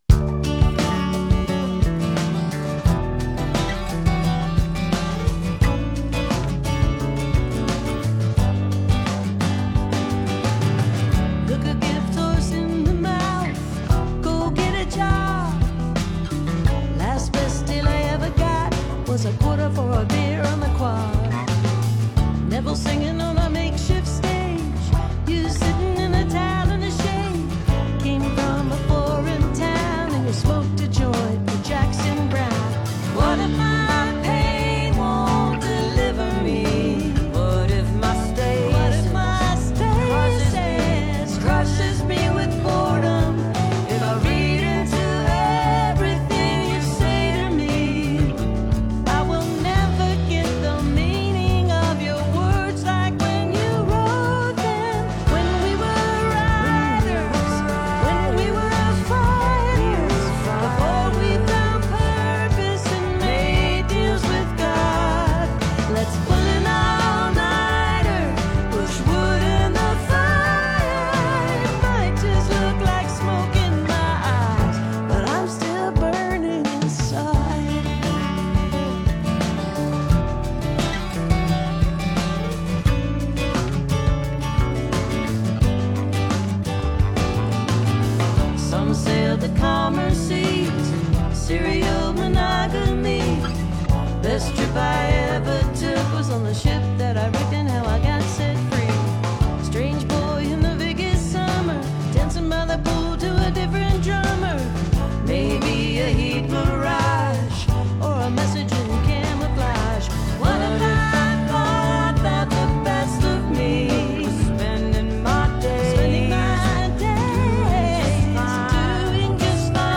(captured from the web broadcast)